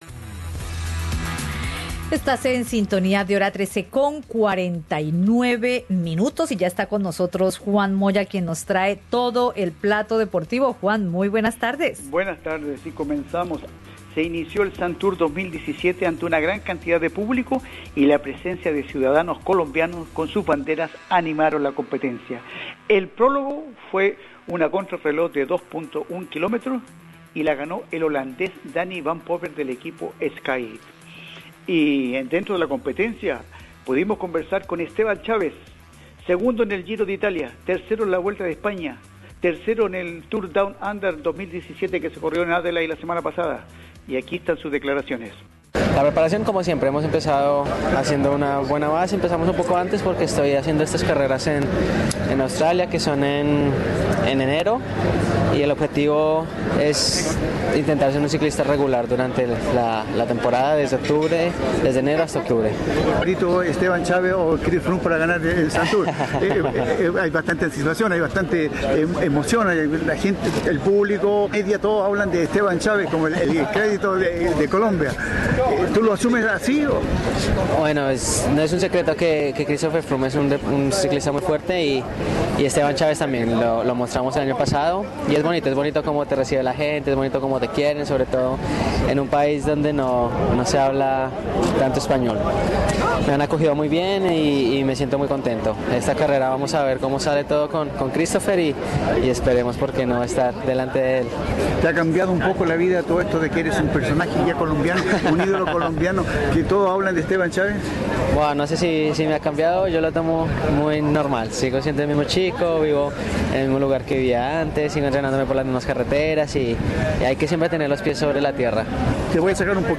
Entrevista con los ciclistas colombianos Esteban Chavez y Sebastian Henao, quienes participan en el Herald Sun Tour 2017 en Australia.